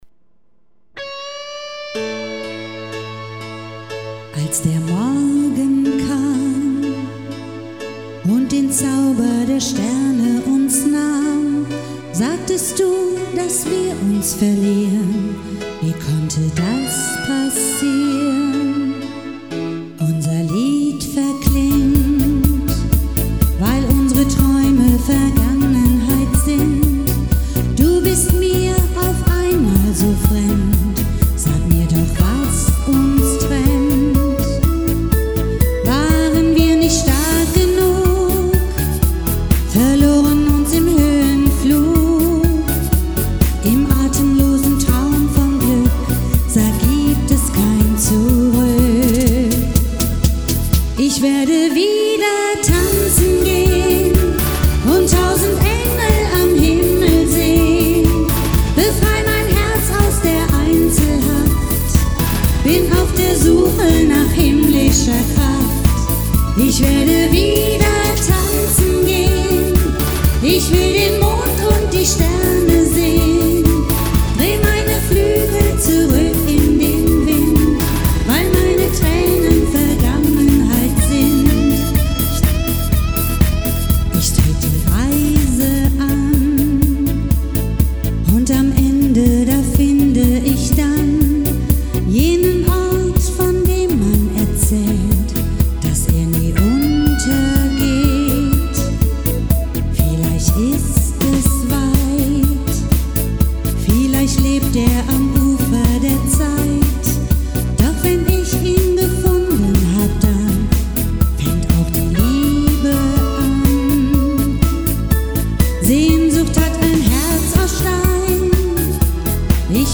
Partyband aus Kerpen Musik für jede Party und Altersklasse.